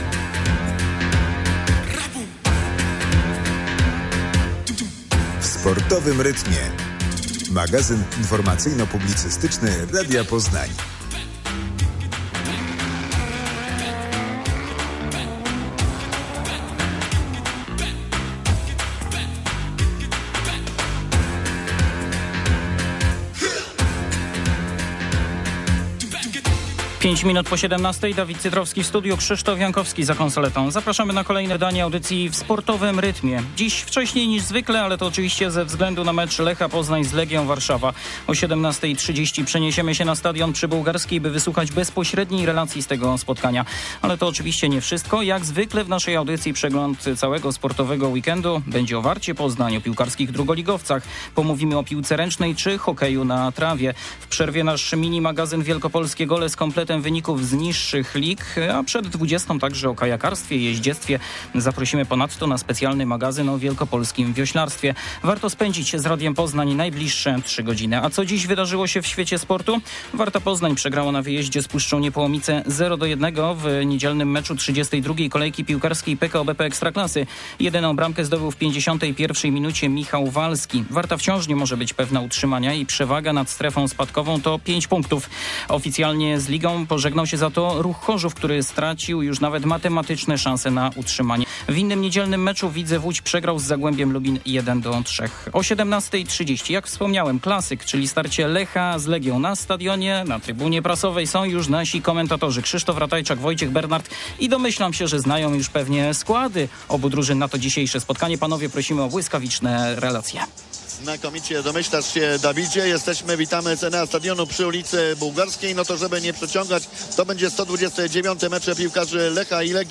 Specjalne wydanie audycji W Sportowym Rytmie, połączone z transmisją meczu Lech - Legia.